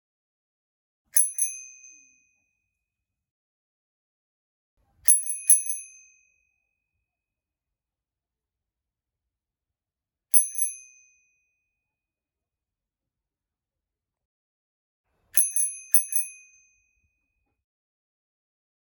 9. Звук велозвонка (несколько разных вариантов с одним звонком)
velozvon-1.mp3